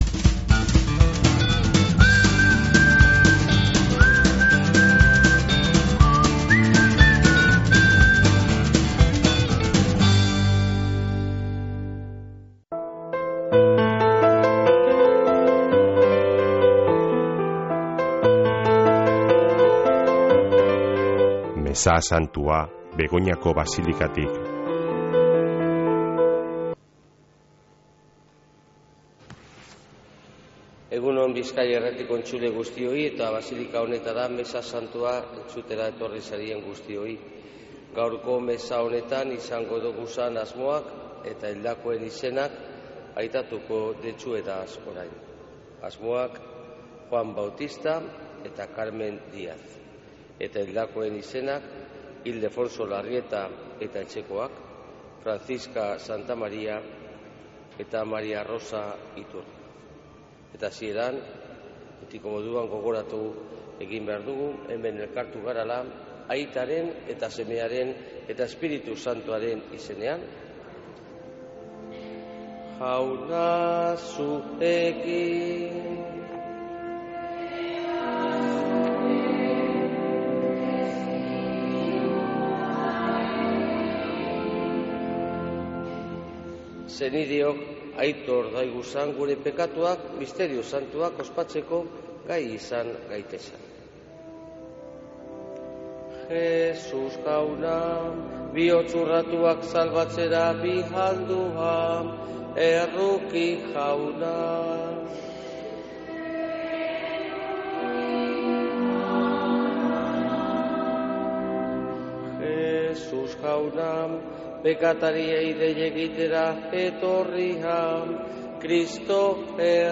Mezea Begoñako Basilikatik | Bizkaia Irratia
MEZEA-BEGONAKO-BASILIKATIK.mp3